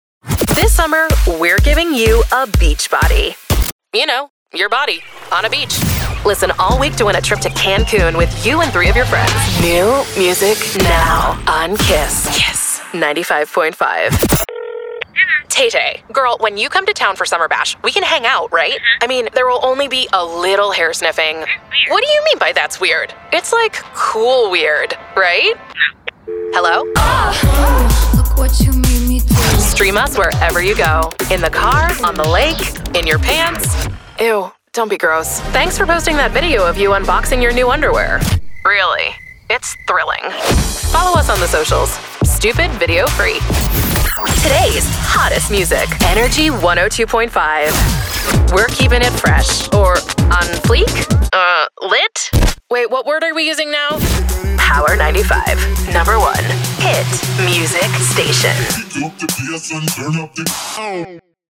Soy la voz femenina estadounidense que estabas buscando.
Maternal